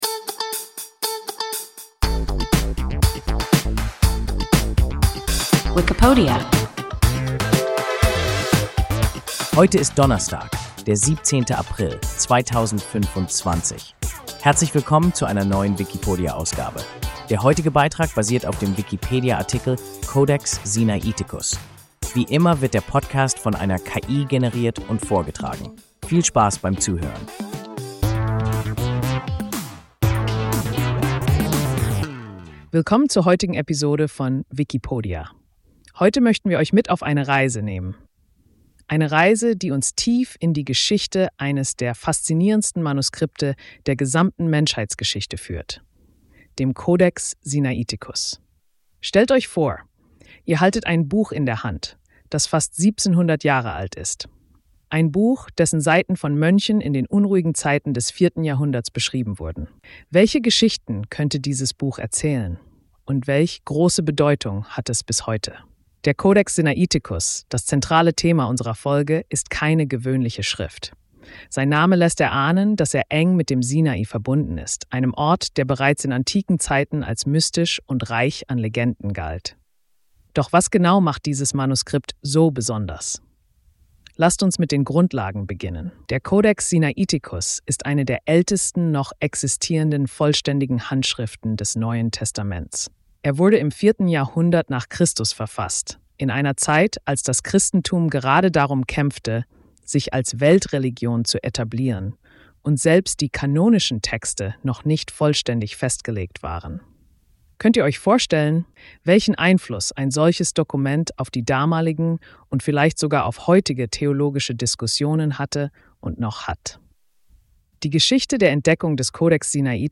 Codex Sinaiticus – WIKIPODIA – ein KI Podcast